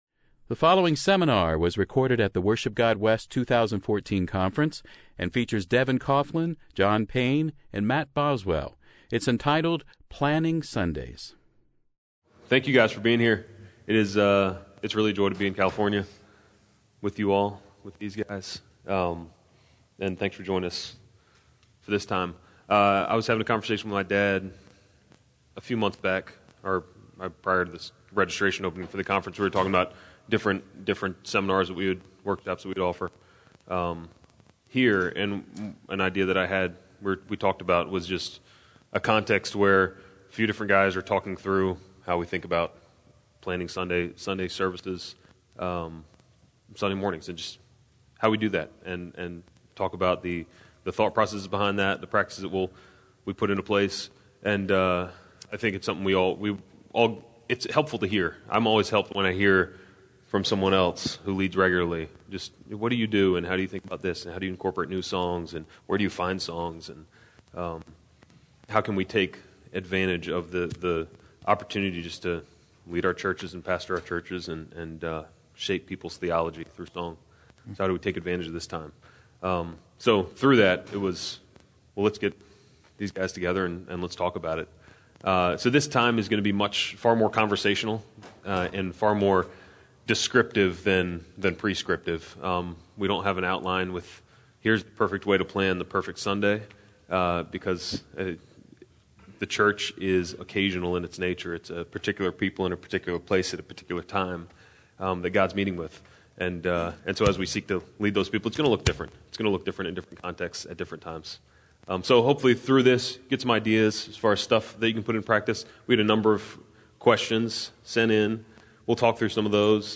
What should we sing this Sunday? Join the conversation as three leaders walk through how they specifically plan for leading in congregational song. Topics of discussion include choosing songs, the structure of our gatherings, introducing new songs, and the questions you (yes, you) bring.